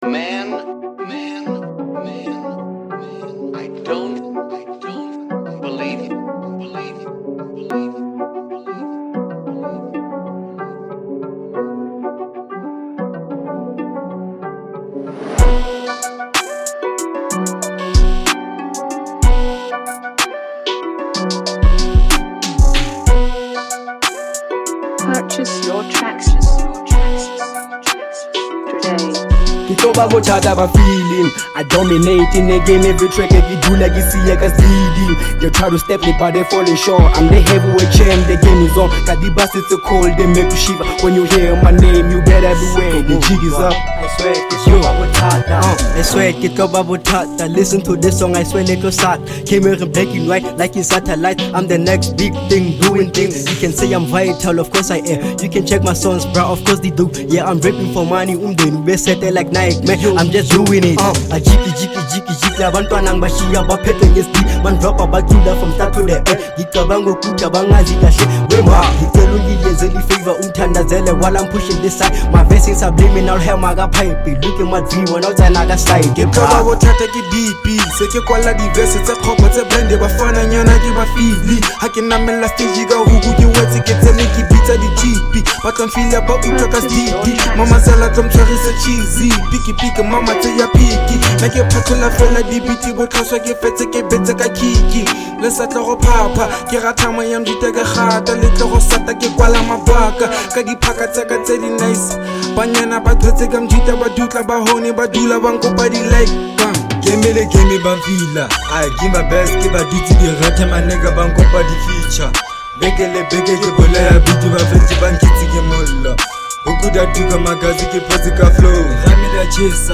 03:39 Genre : Trap Size